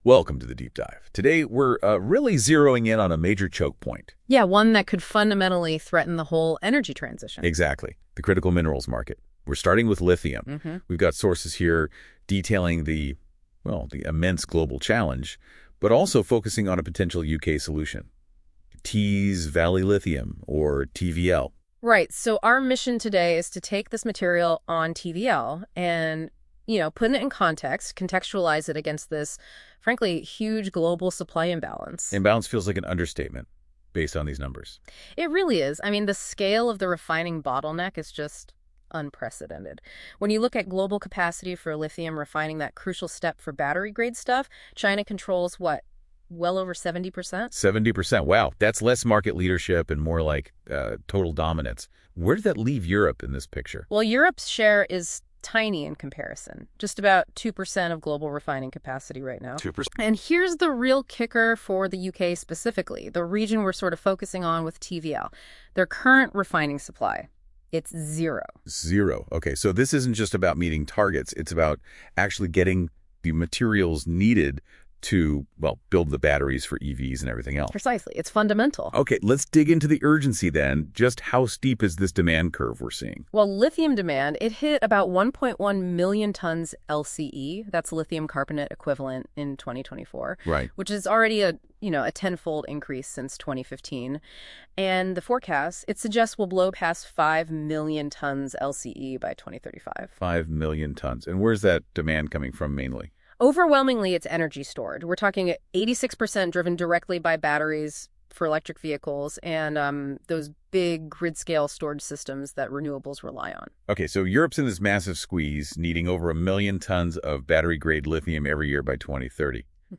These AI generated audio overviews are an interesting way to listen to a summary of the publications and presentations by ALK/TVL